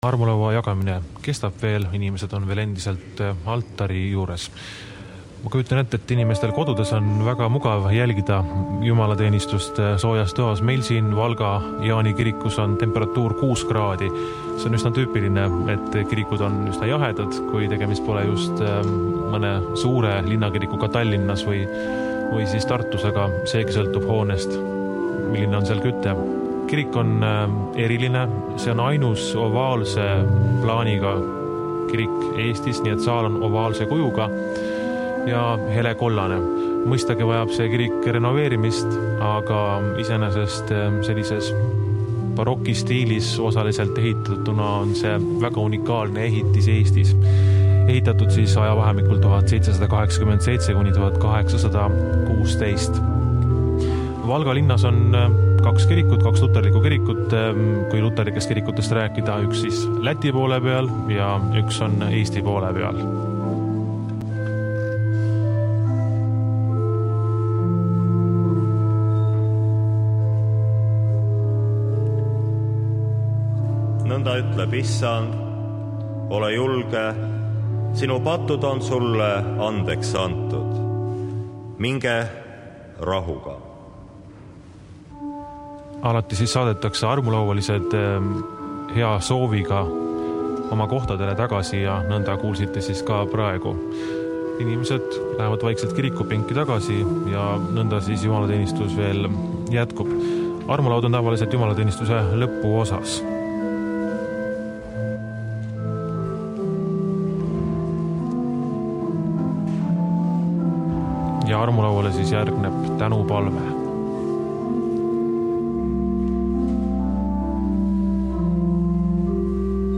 Urkujen
principal-sointia, pehmeitä
huiluja (sekä "viuluja") ja